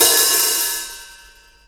Open_Cym_1.wav